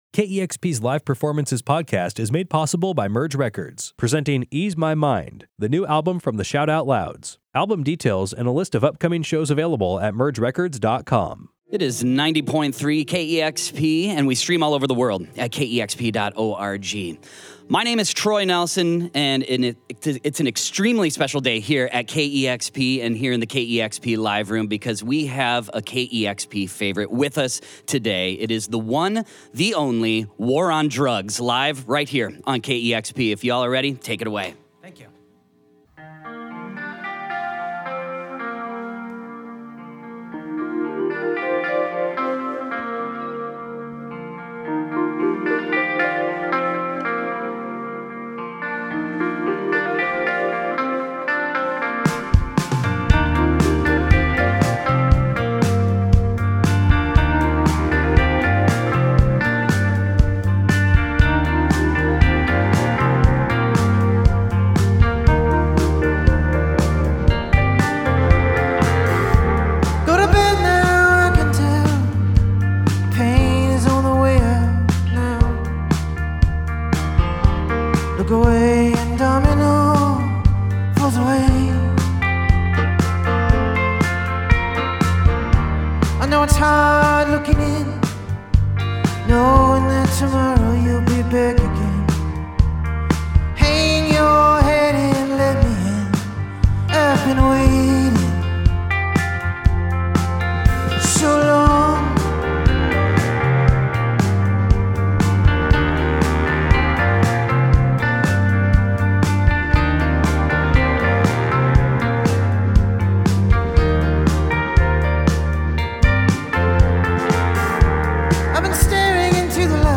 sublime session
Featuring four songs off the band's beautiful new album